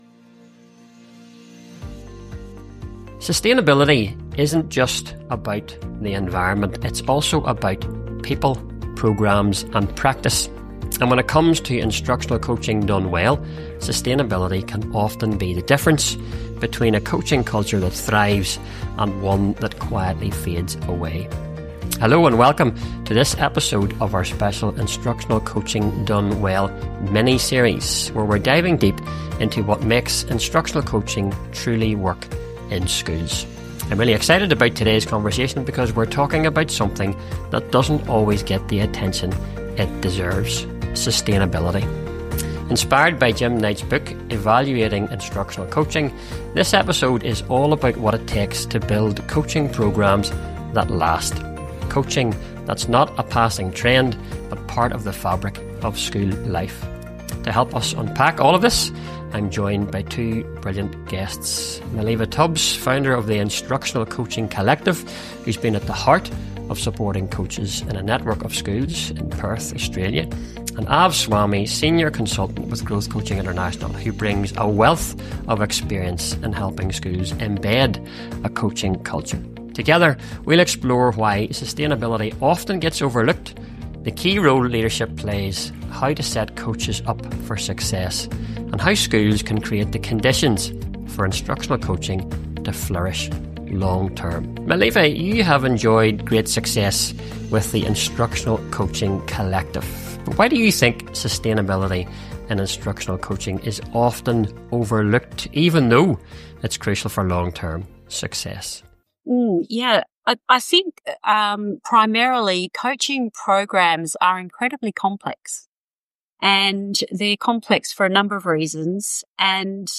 In this conversation we’re talking about something that doesn’t always get the attention it deserves — sustainability. Inspired by Jim Knight’s book Evaluating Instructional Coaching, this episode is all about what it takes to build coaching programs that last — coaching that’s not a passing trend, but part of the fabric of school life.